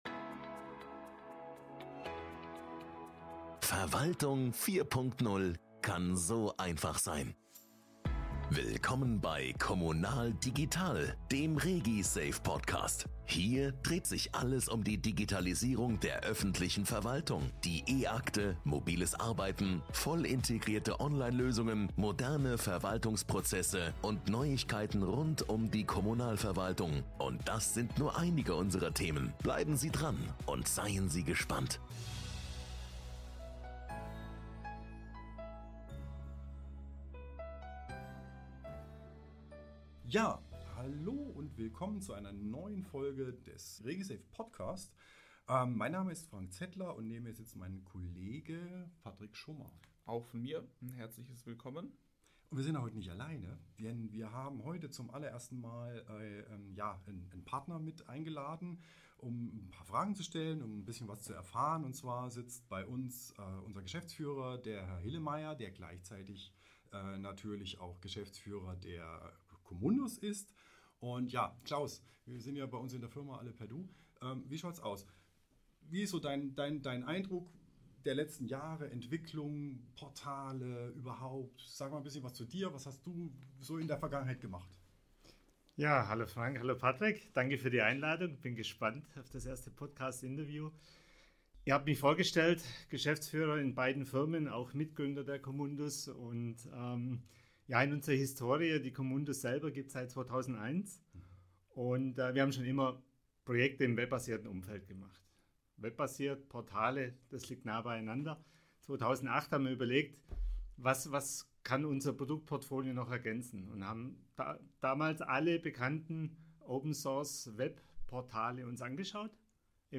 Die Möglichkeiten für den Einsatz von Online-Portalen sind schier endlos. regisafe-Portallösungen wie das Ratsinformationssystem, Stellenbesetzung Online, Online-Anträge und das Projektportal sind bereits erfolgreich im Einsatz – weitere Portale in Planung. Mehr dazu erfahren Sie im Interview.